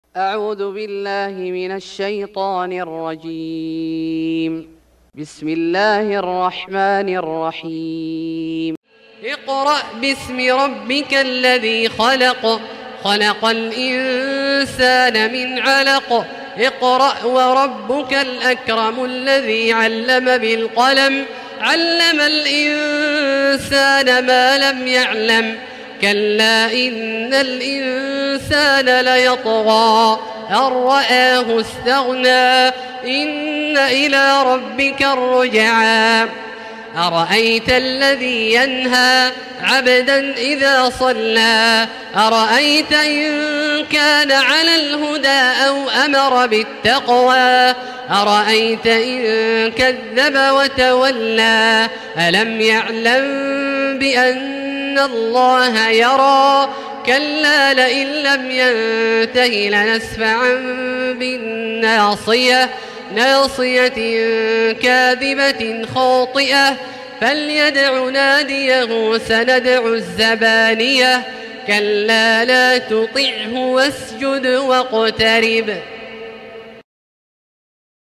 سورة العلق Surat Al-Alaq > مصحف الشيخ عبدالله الجهني من الحرم المكي > المصحف - تلاوات الحرمين